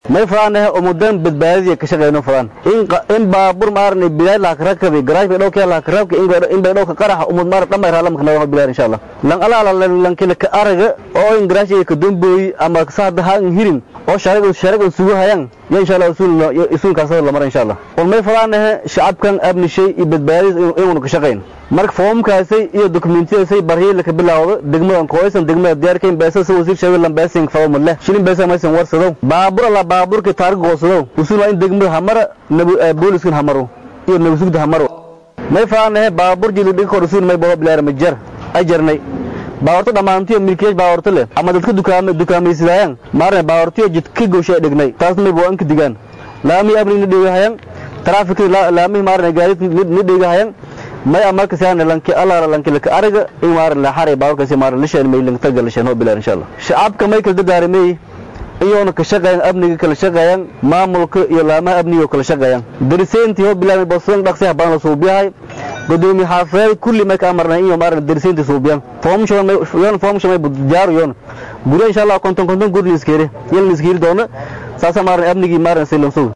Gudoomiyaha magaalada Beydhabo C/laahi Cali Maxamed Watiin ayaa waxa uu ka hadlay ula jeedada kulanka iyo waxyaabaha looga baahan yahay milkiilayaasha.
Codka-Gudoomiye-Watiin.mp3